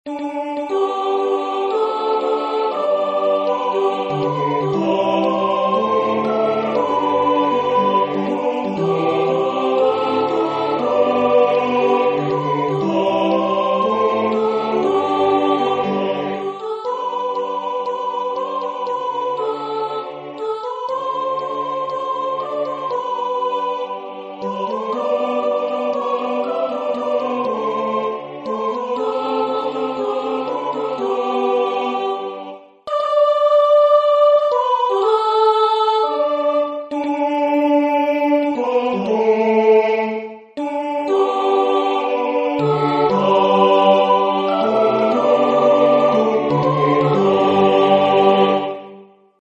La Citoyenne, choral à 4 voix, instruments